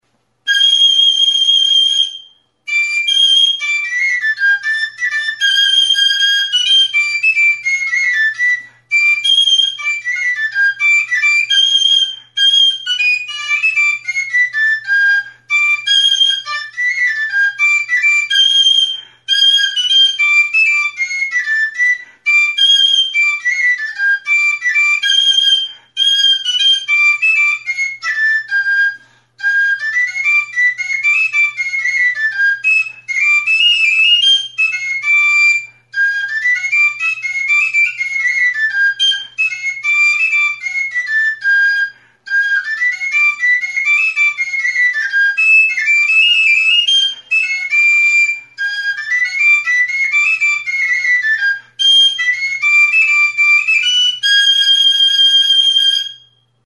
Aerophones -> Flutes -> Fipple flutes (one-handed)
Recorded with this music instrument.
Hiru zuloko flauta zuzena da, ezpelezko txirula.